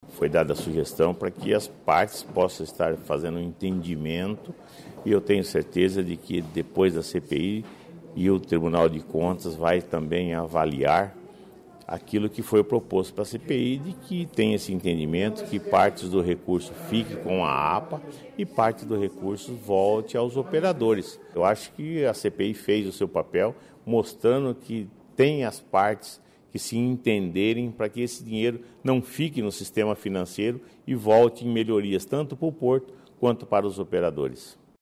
Os deputados que compõem a Comissão Parlamentar de Inquérito das Tarifas Portuárias aprovaram nesta segunda-feira (12) o relatório final dos trabalhos de investigação. O presidente da CPI, deputado Fernando Scanavaca (PDT), explica a principal recomendação para resolver o impasse em torno de parte da tarifa portuária.